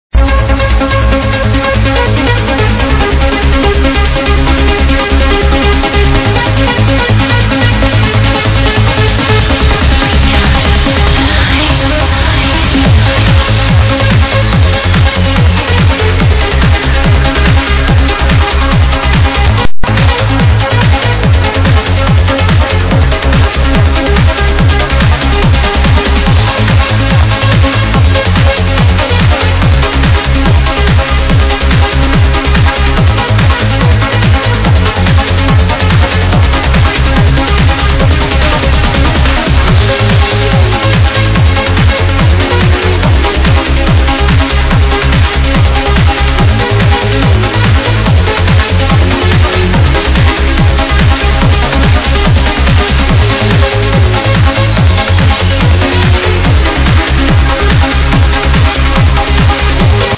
Hello! Trance tune from 2001
sped up a bit Beautiful track